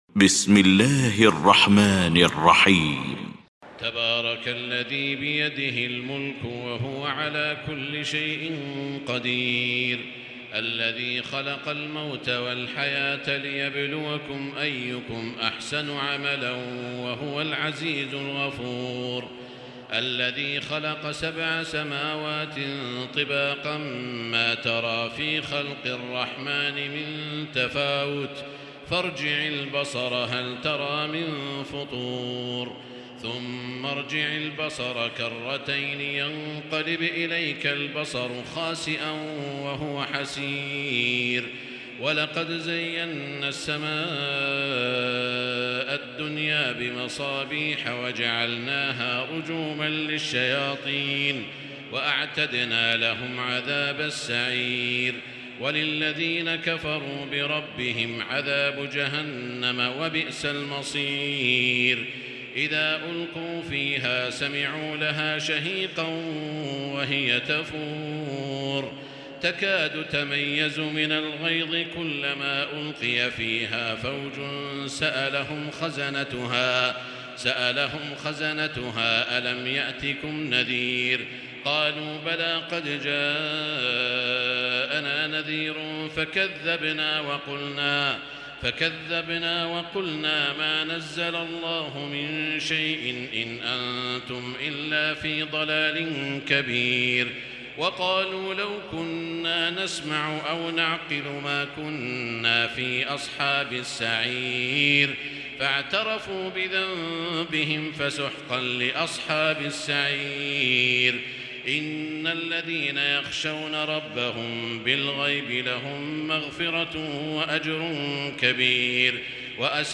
المكان: المسجد الحرام الشيخ: سعود الشريم سعود الشريم الملك